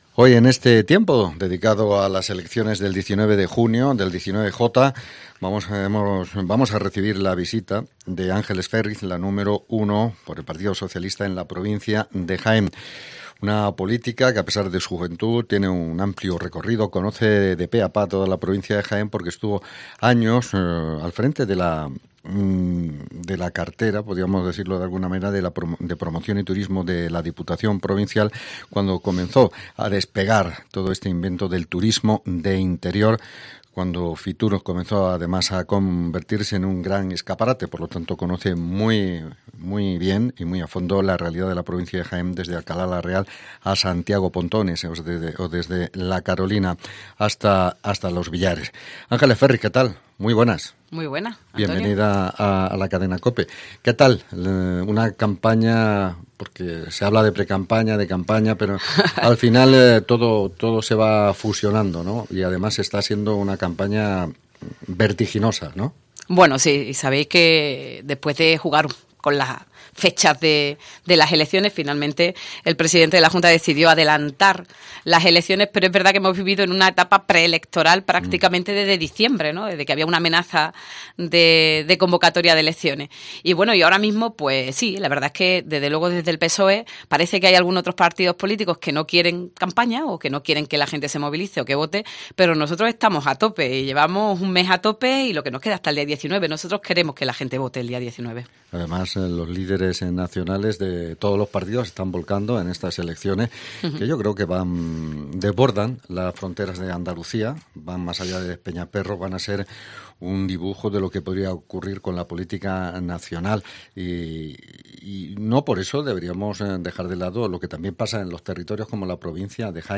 La número dos del PSOE andaluz y cabeza de lista por Jaén ha pasado por los estudios de COPE para exponer su programa electoral de cara al 19-J